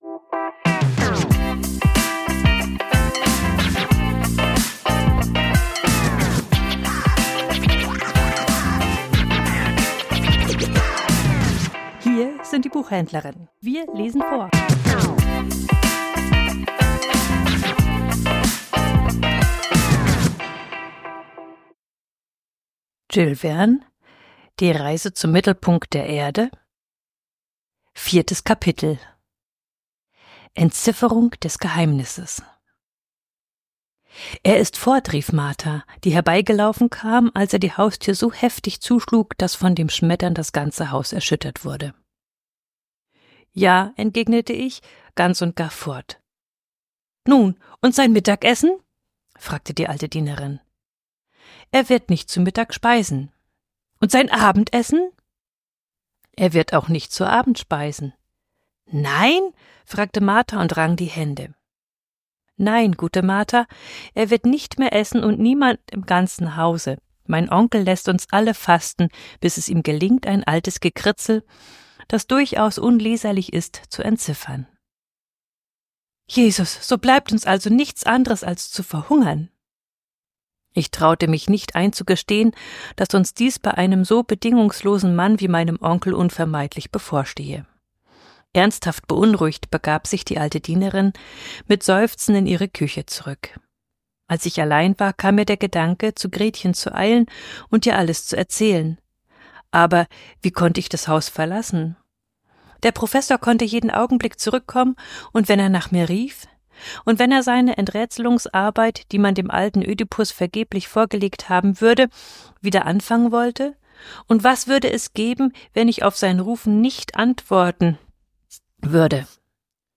Vorgelesen: Die Reise zum Mittelpunkt der Erde ~ Die Buchhändlerinnen Podcast